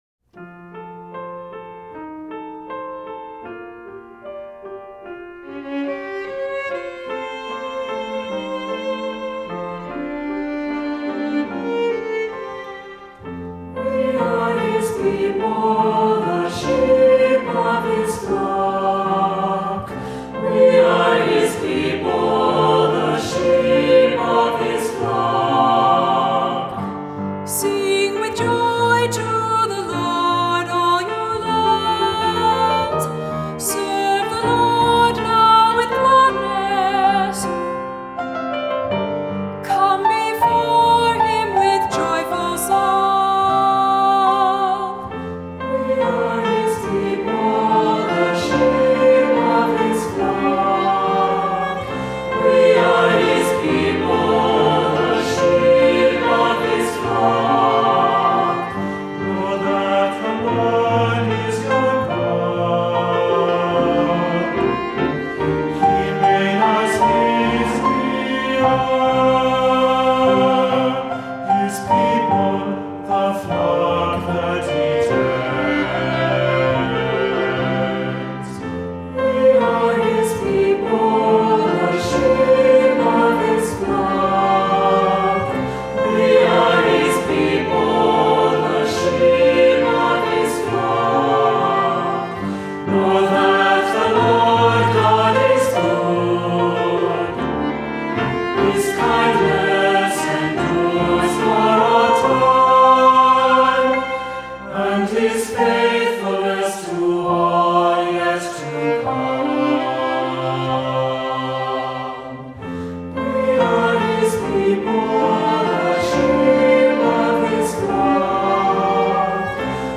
Voicing: Cantor, assembly,SATB